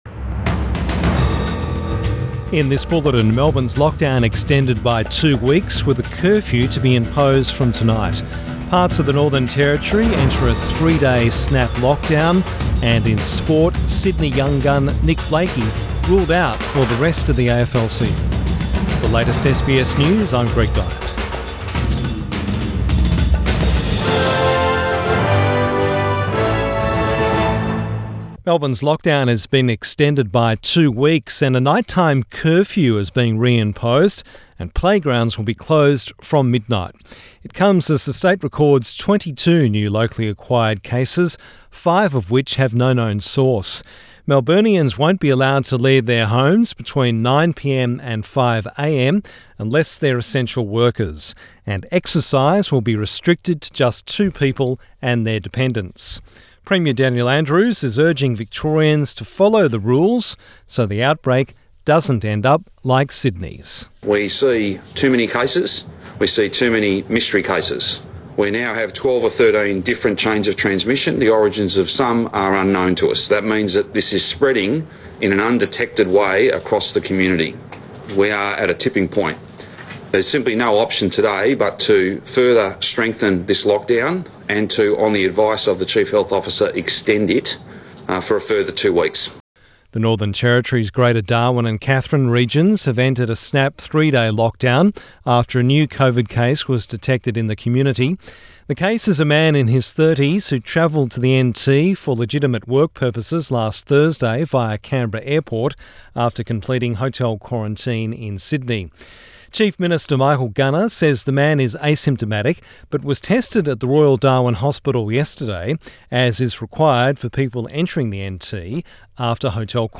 PM bulletin 16 August 2021